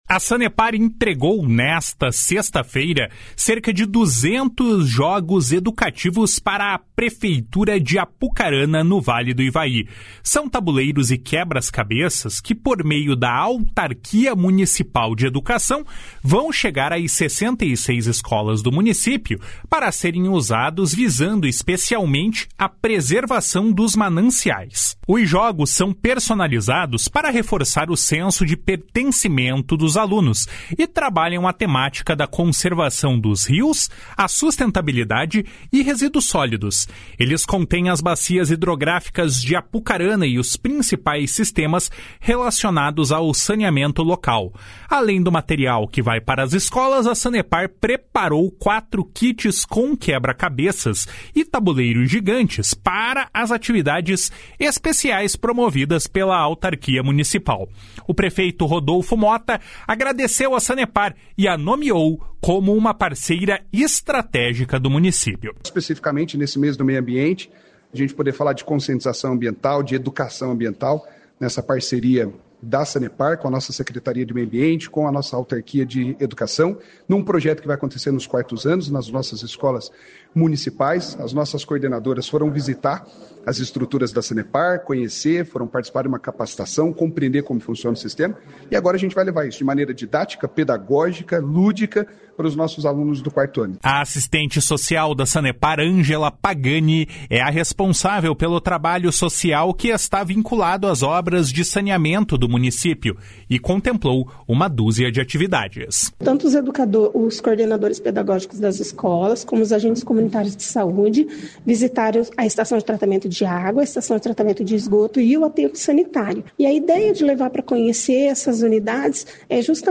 O prefeito Rodolfo Mota agradeceu a Sanepar e a nomeou como uma parceira estratégica do município.